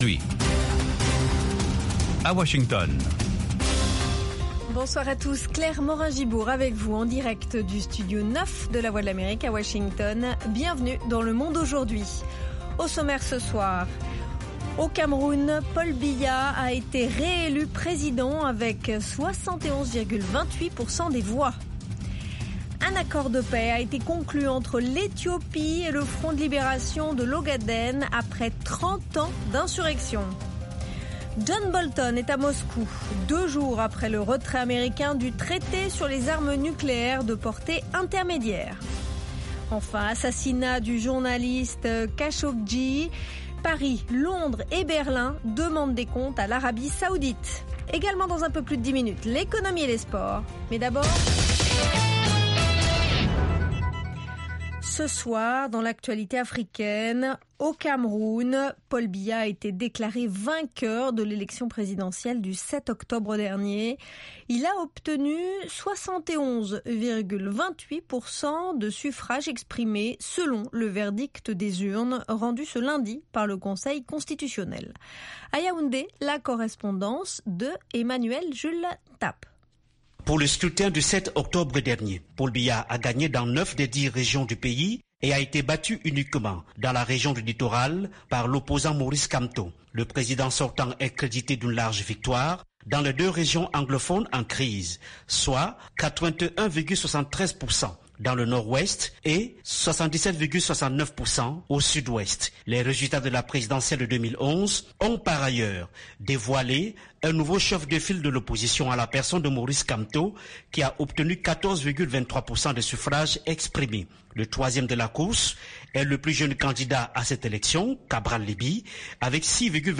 Interviews, reportages de nos envoyés spéciaux et de nos correspondants, dossiers, débats avec les principaux acteurs de la vie politique et de la société civile. Le Monde Aujourd'hui vous offre du lundi au vendredi une synthèse des principaux développementsdans la région.